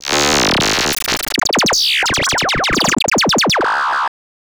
Glitch FX 36.wav